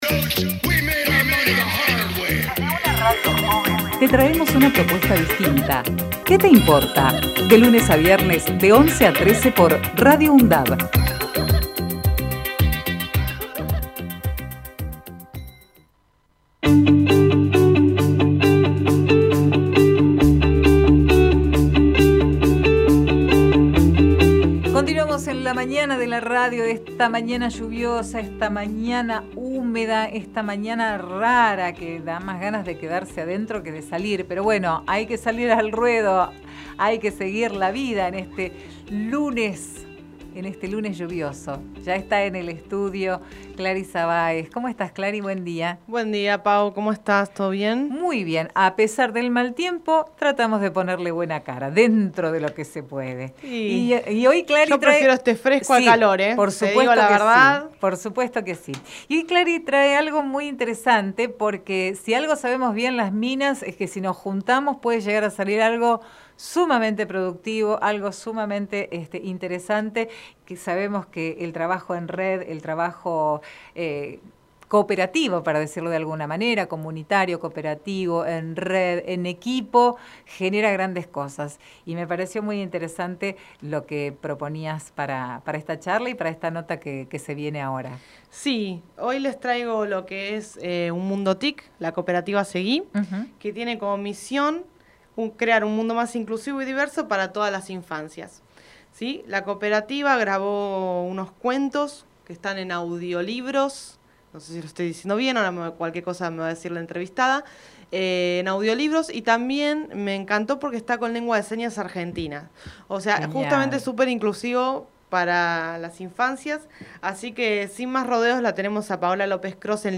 Compartimos la entrevista realizada en "Que te importa?!"